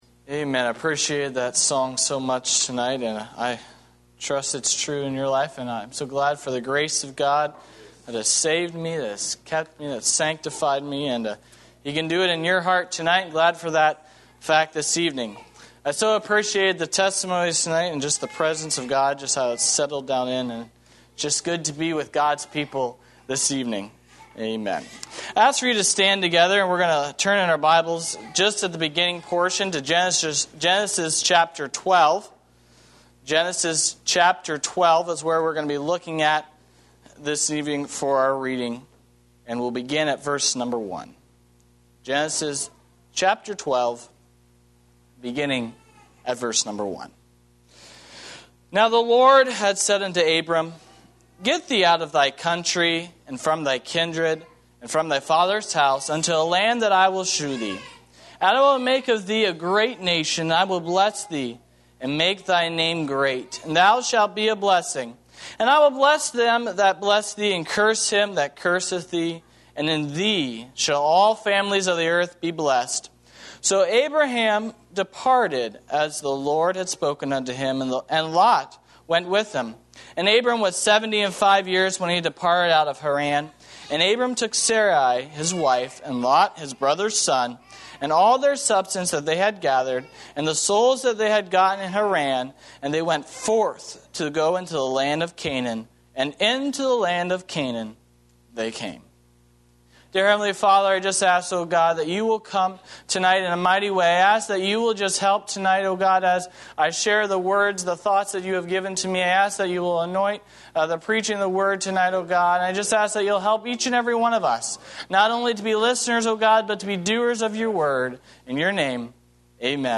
A sermon